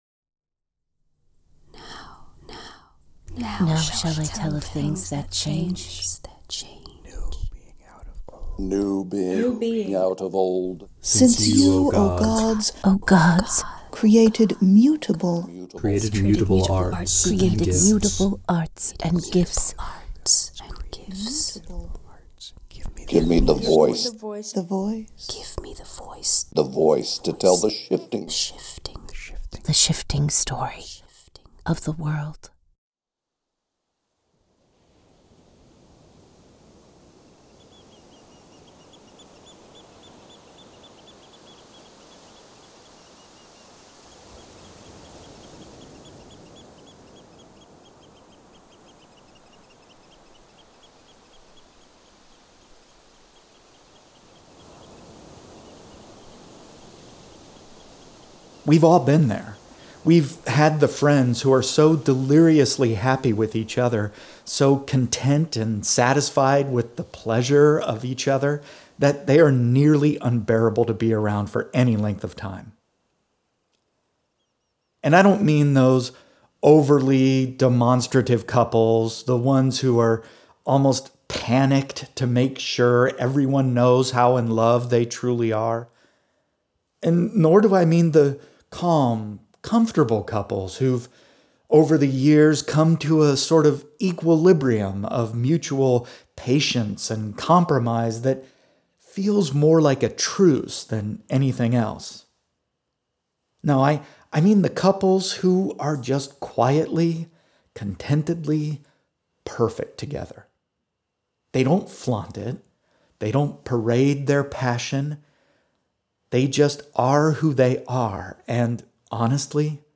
The closing music